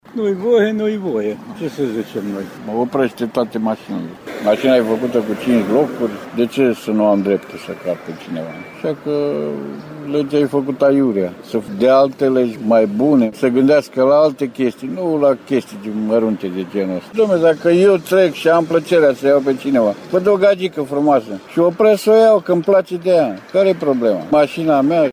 Ascultaţi ce părere au şoferi de pe Calea Caransebeşului din Reşiţa, care nu deţin autorizaţie despre această lege: